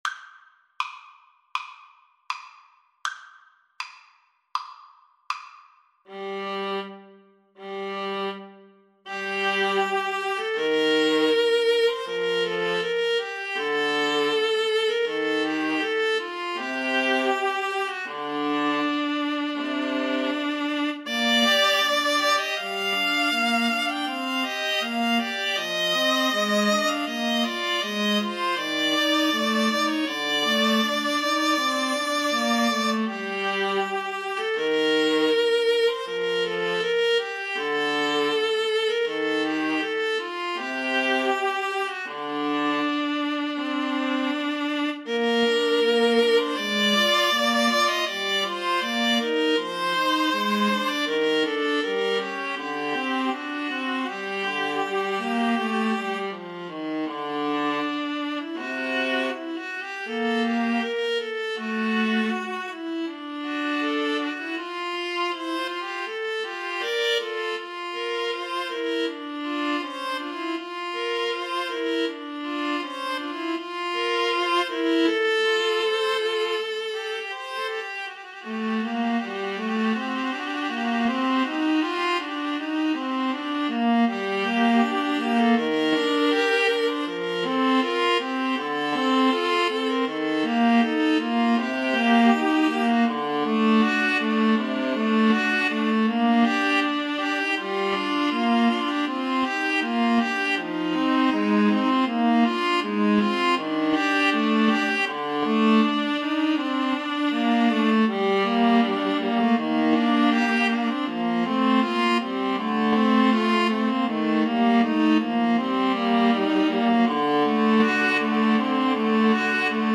=100 Andante
Classical (View more Classical Viola Trio Music)